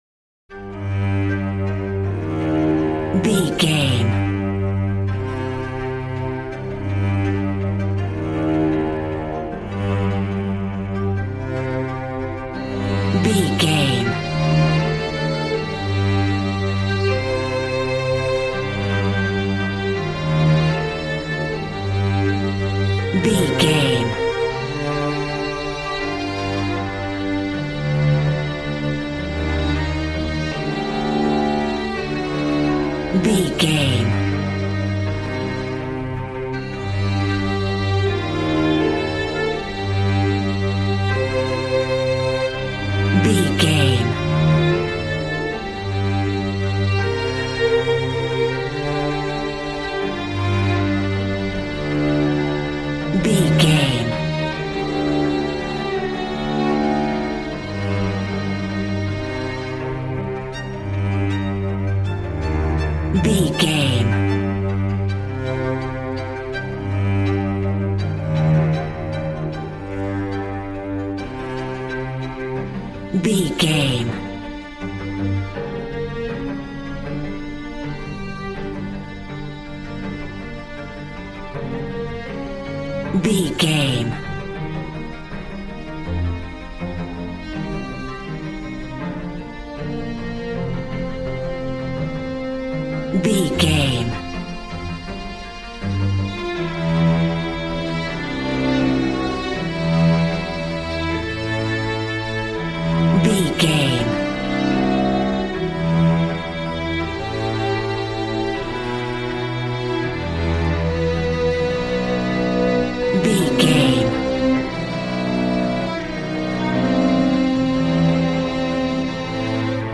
Ionian/Major
regal
brass